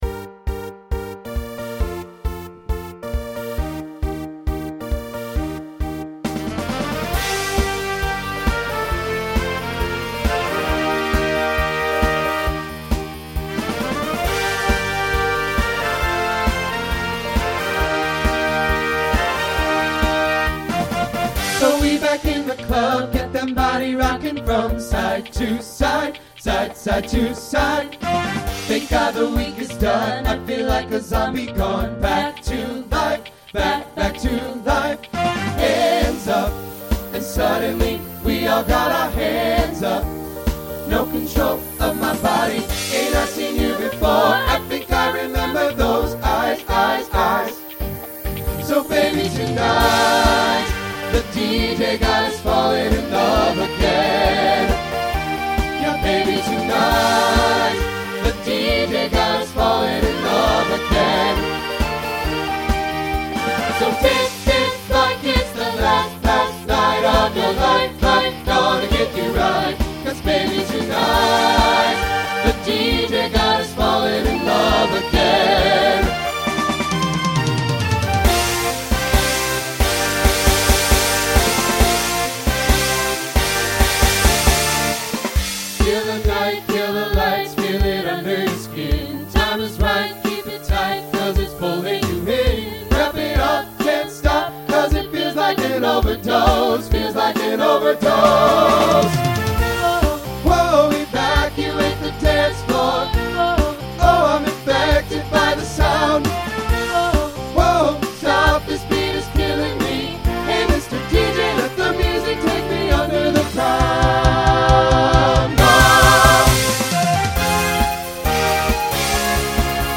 Voicing SATB Instrumental combo Genre Pop/Dance , Rock